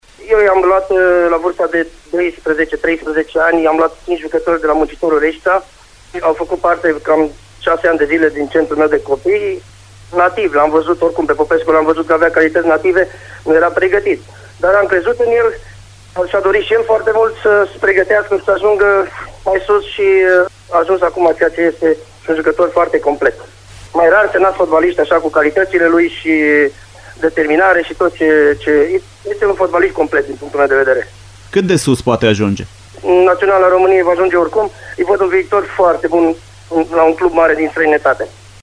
Interviul complet va fi sâmbătă, la “Arena Radio”, emisiunea difuzată între orele 11,00 si 13,00.